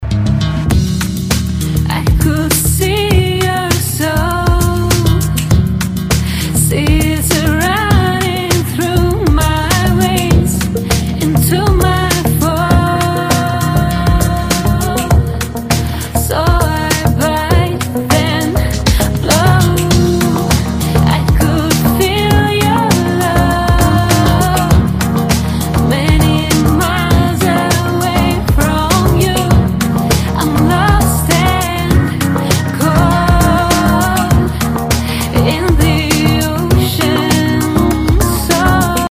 • Качество: 320, Stereo
спокойные
красивый женский голос
расслабляющие